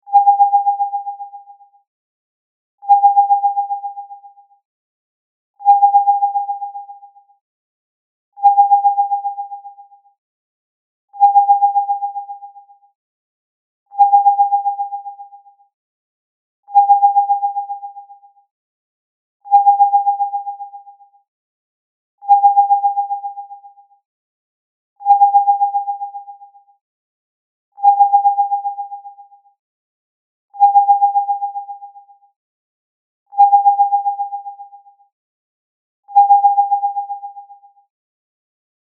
レーダーの音をイメージした着信音。